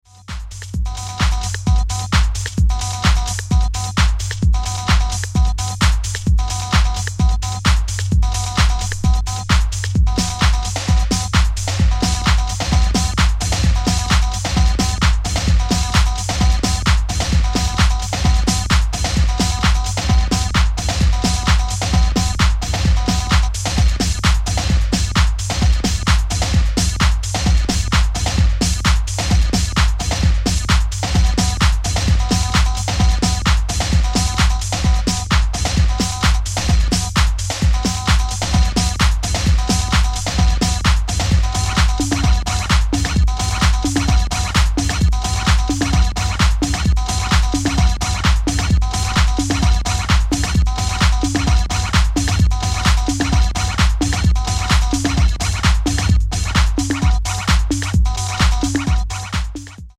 Obscure hard-to-find raw Chicago five track ep from 1992.
House